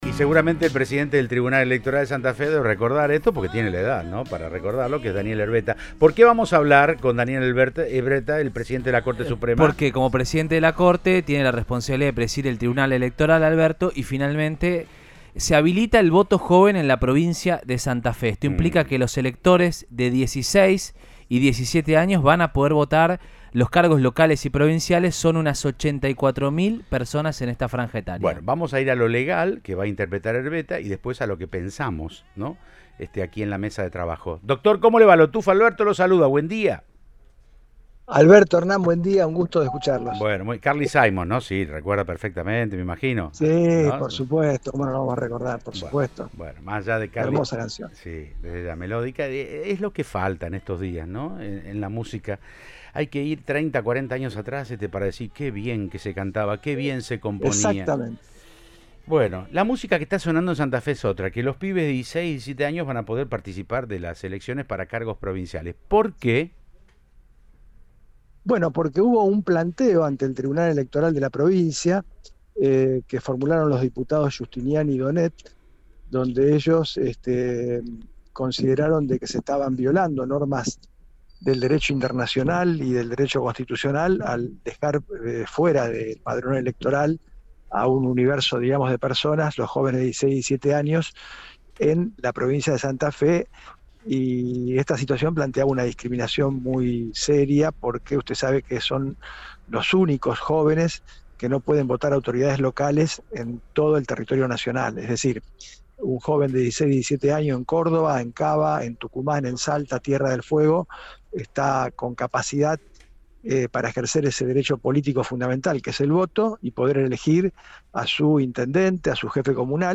El presidente del Tribunal Electoral explicó cómo se llegó a la determinación que habilita, de forma optativa, a jóvenes de 16 y 17 años a votar.